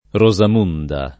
Rosamunda [ ro @ am 2 nda ]